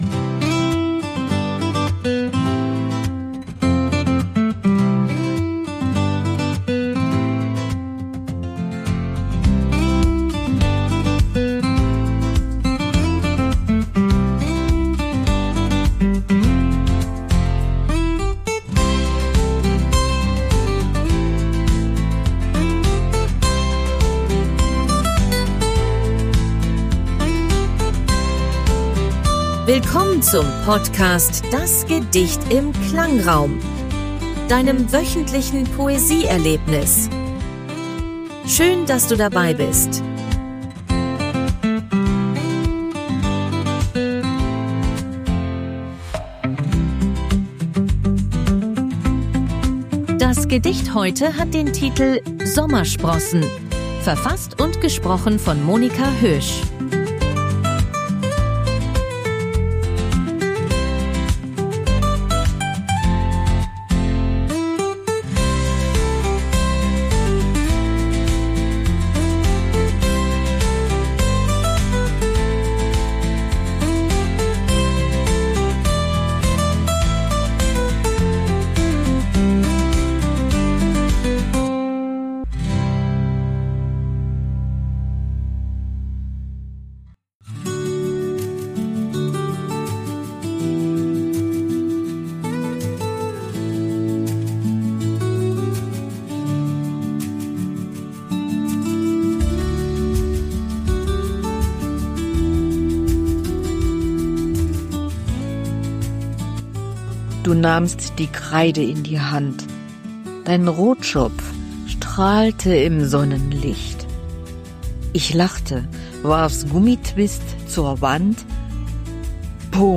stimmungsvollen Klangkulisse.
- begleitet von KI-generierter Musik.